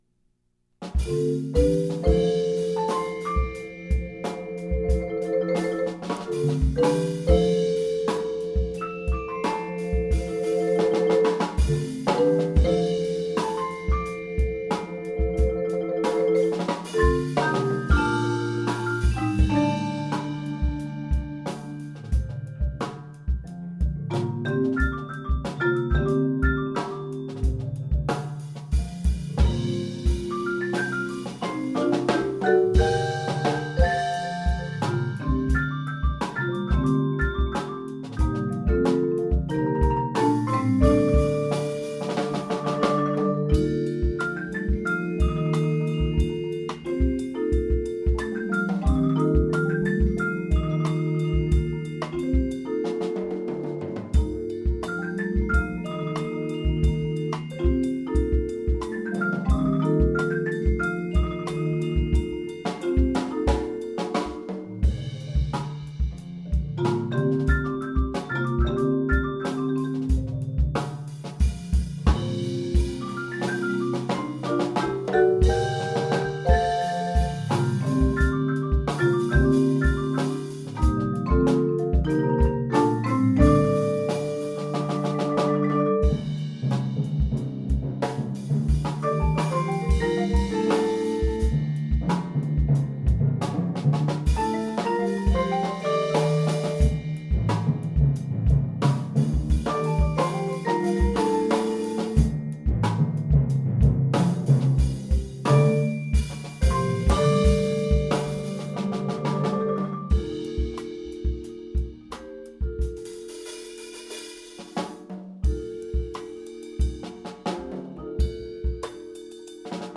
Genre: Percussion Ensemble
# of Players: 8
A keyboard-based percussion ensemble work
features a jazz feel
Bells
Xylophone
Vibraphone
Marimba 1*
Timpani
Electric Bass (Optional Marimba 3)
Drum Set